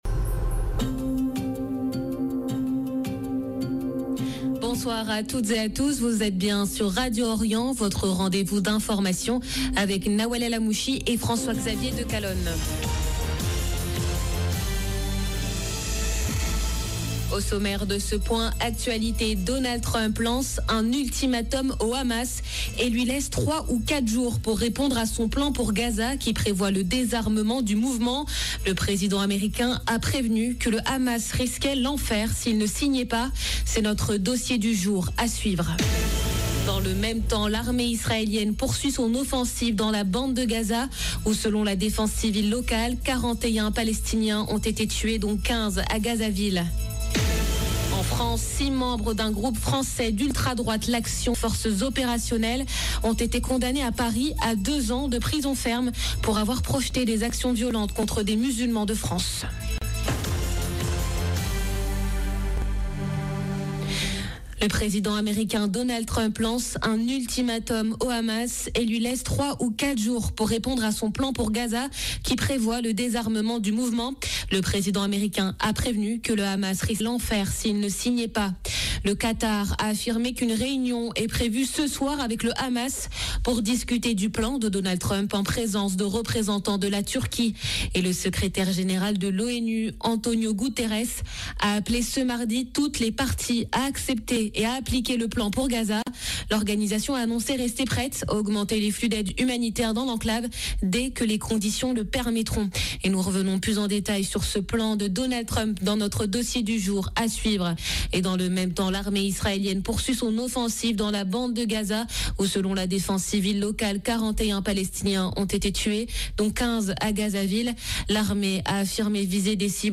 JOURNAL DE 17H DU 30/09/2025